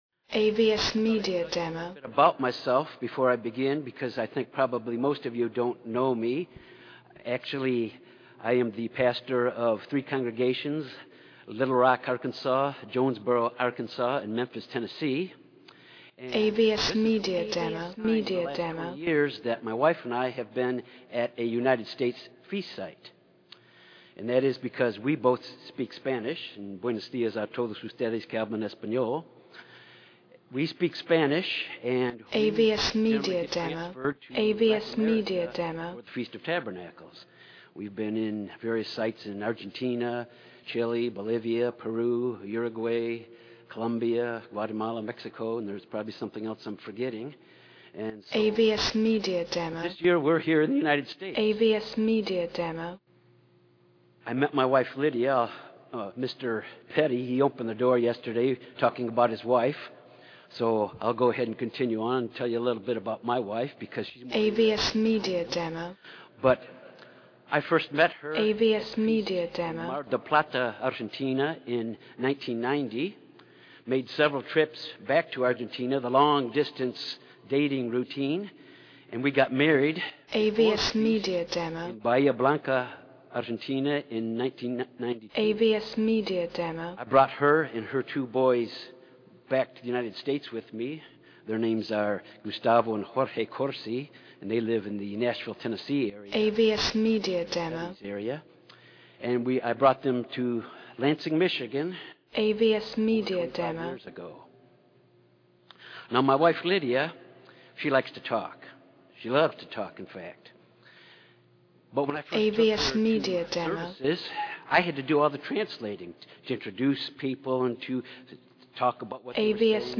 This sermon was given at the Galveston, Texas 2018 Feast site.